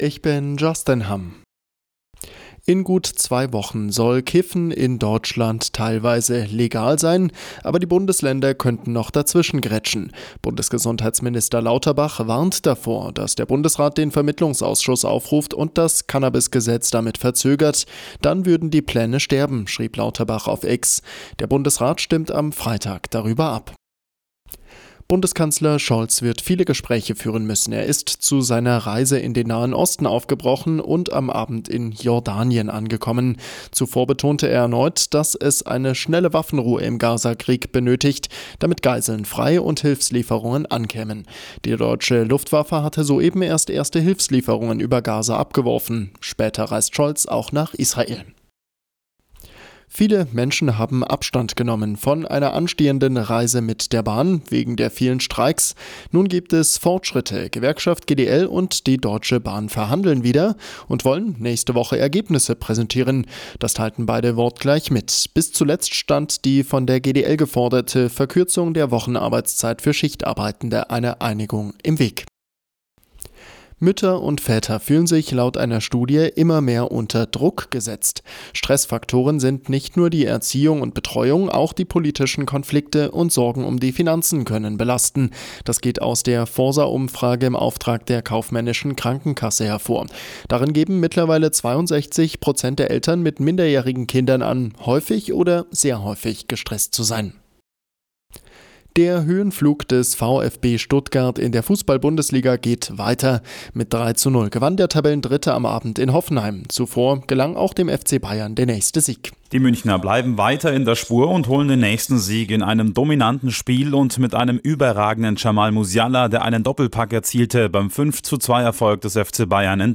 Die Arabella Nachrichten vom Sonntag, 17.03.2024 um 06:06 Uhr - 17.03.2024